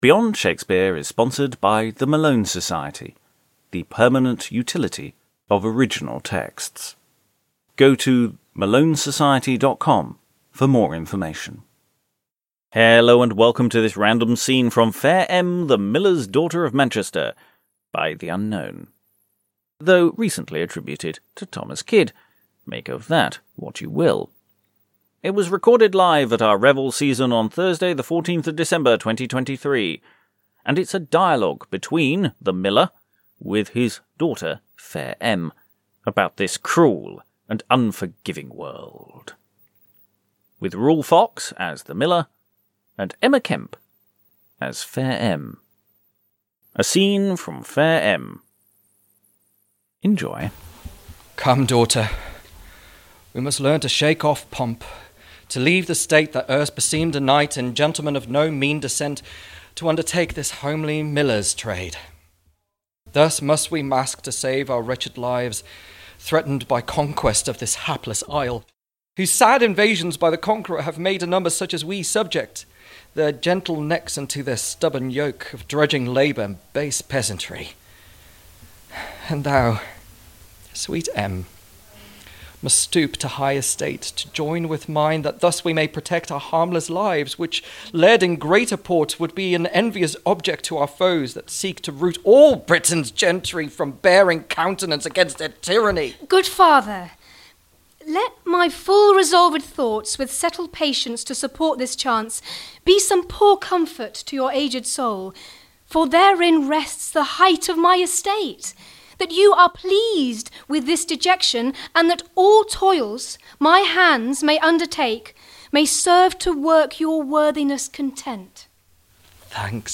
It was recorded live at our Winter Revels season on Thursday 14th December 2023. It is a dialogue between the Miller, with his daughter Fair Em, about this cruel and unforgiving world.